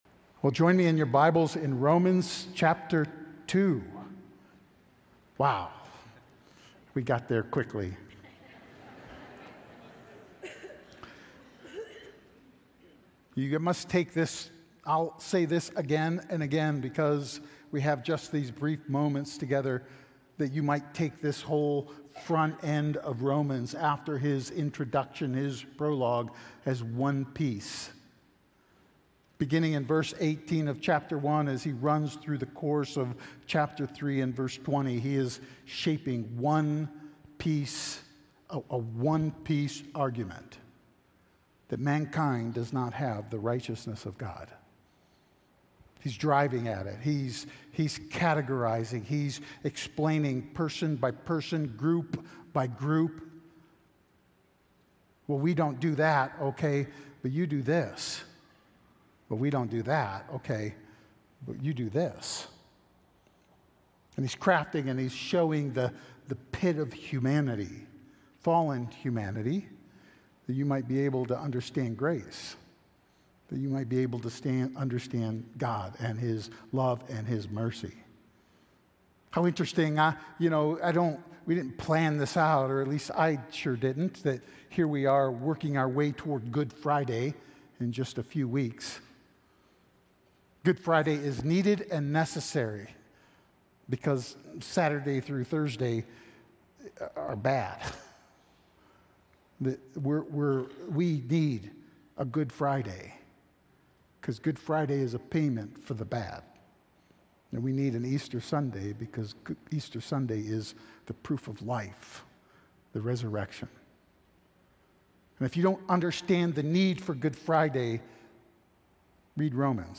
The Power of God Audio File Sermon Notes More From This Series Farewell...